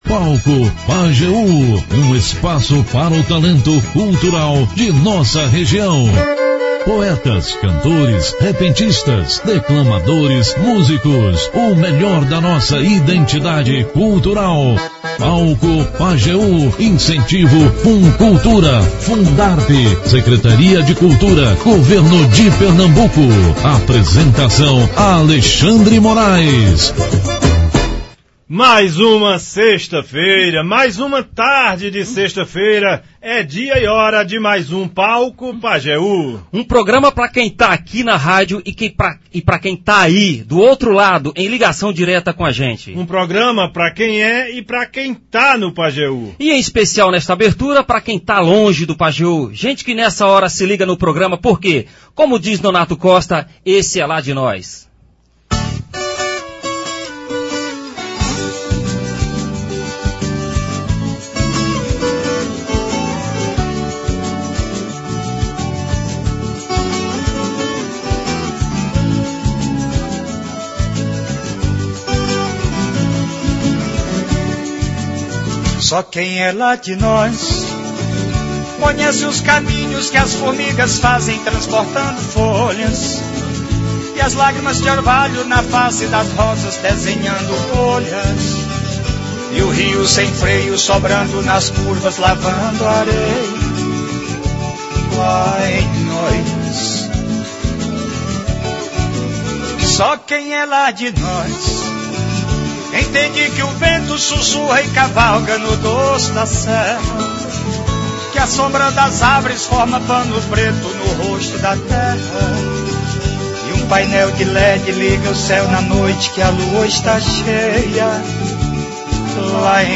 Confira abaixo em áudio ou vídeo: O Palco Pajeú é um espaço para o talento cultural da região, com poetas, cantores, repentistas, declamadores, músicos e o melhor da identidade cultural do sertanejo.